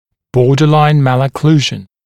[‘bɔːdəlaɪn ˌmælə’kluːʒn][‘бо:дэлайн ˌмэлэ’клу:жн]пограничная аномалия прикуса